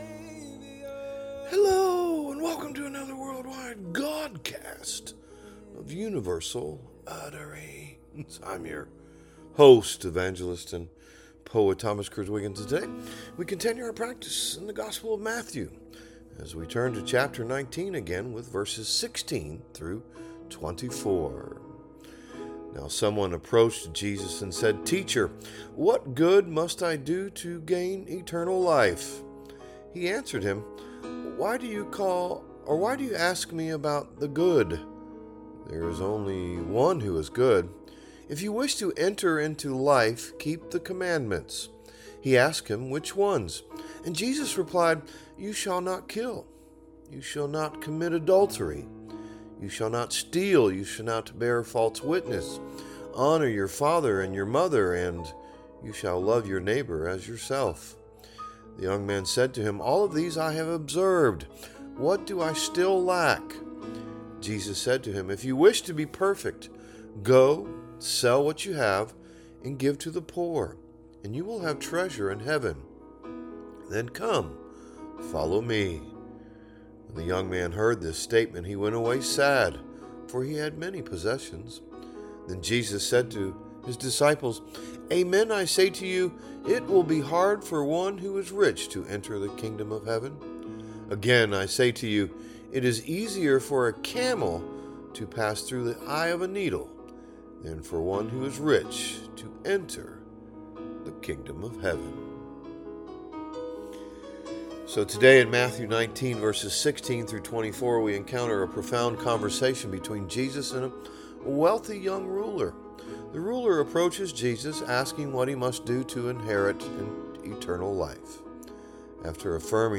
A Godcast